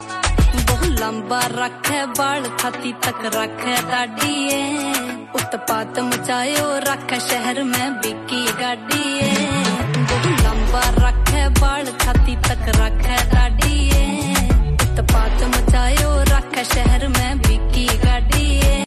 Rajasthani songs
• Simple and Lofi sound
• Crisp and clear sound